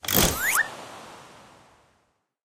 zoom_out_v01.ogg